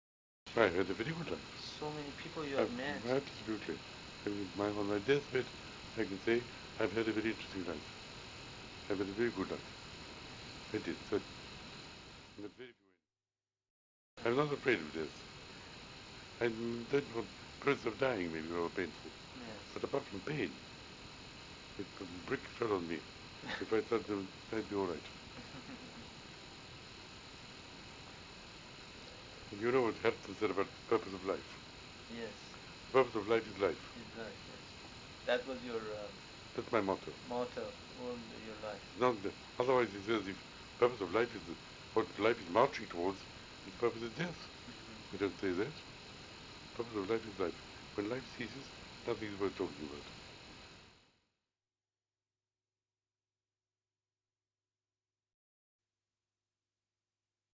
The voice changed enormously in later years, especially after one of Berlin’s vocal cords became partly paralysed.
recording is terrible, but here is part of what Berlin said about life and death: